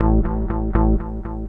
Pulsing Bass.wav